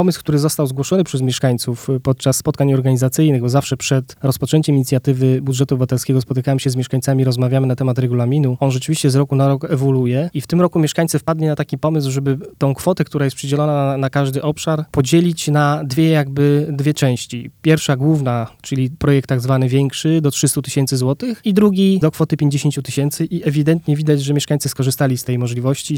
– wyjaśnia sekretarz miasta – Zdzisław Rygiel.